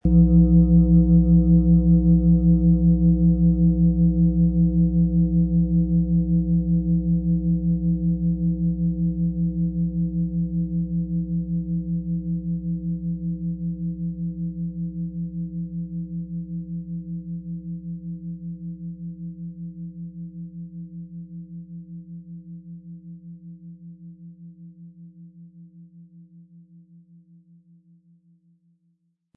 Wir haben diese Schale beim Aufnehmen angespielt und den subjektiven Eindruck, dass sie alle Körperregionen gleich stark anspricht.
Unter dem Artikel-Bild finden Sie den Original-Klang dieser Schale im Audio-Player - Jetzt reinhören.
MaterialBronze